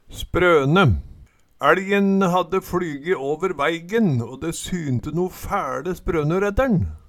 sprøne - Numedalsmål (en-US)